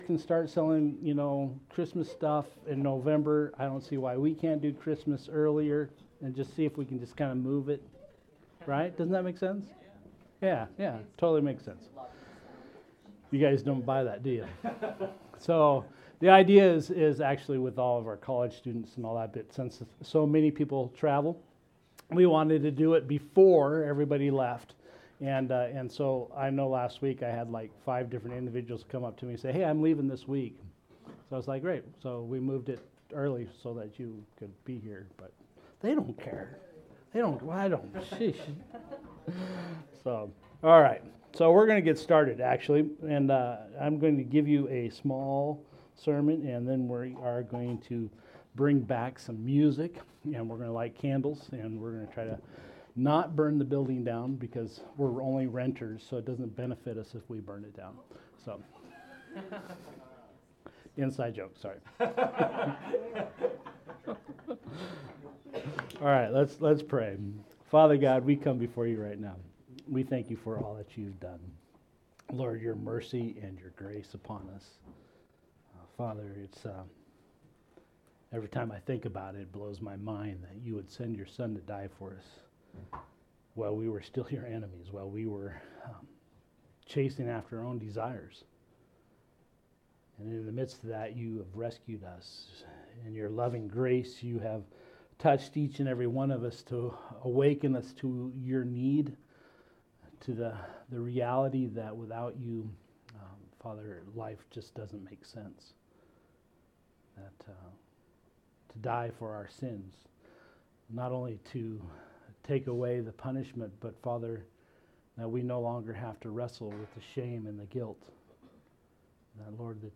Sermons | Explore Church
Candle Light Service 2024